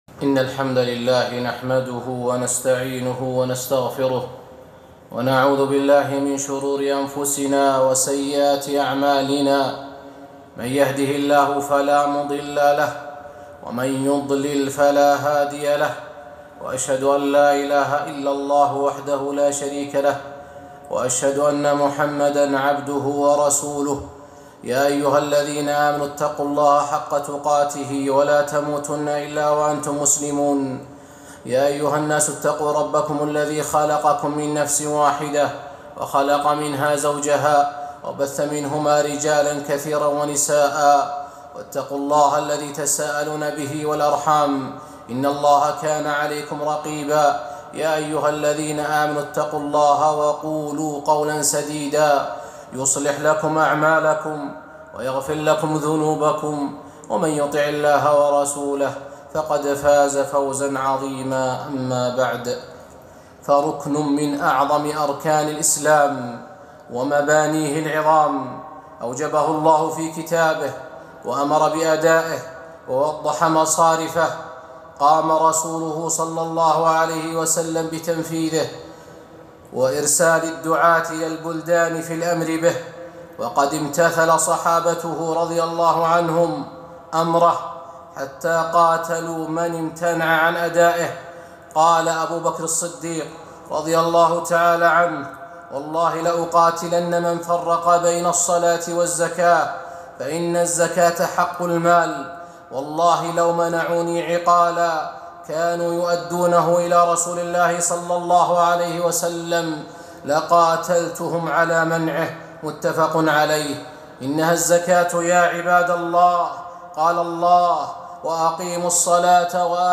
خطبة - إيتاء الزكاة